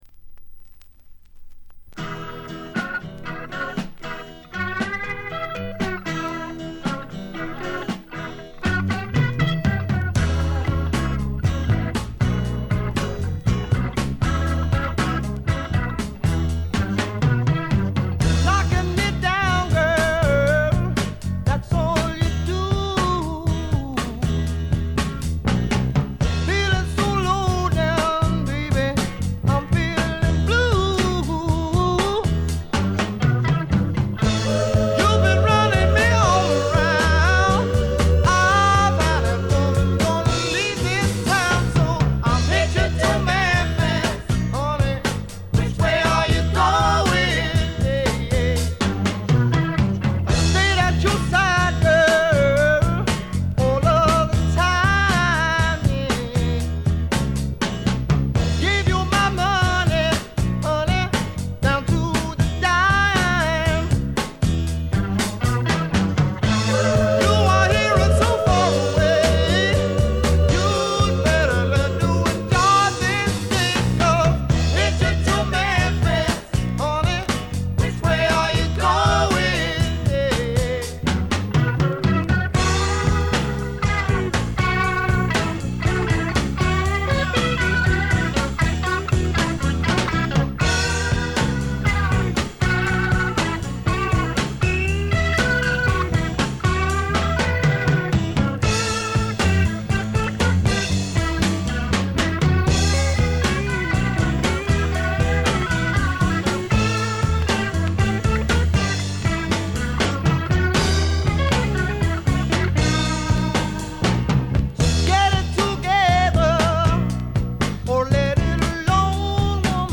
で、内容はザ・バンドからの影響が色濃いスワンプ裏名盤であります。
試聴曲は現品からの取り込み音源です。